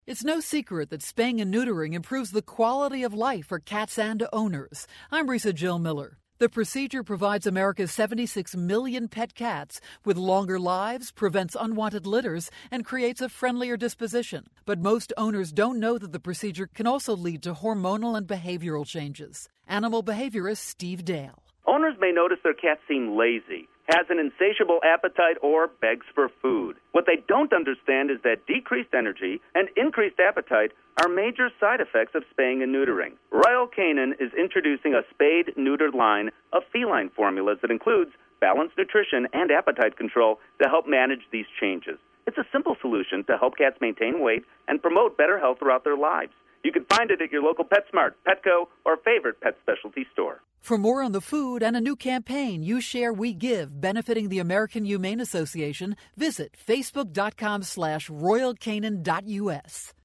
September 7, 2012Posted in: Audio News Release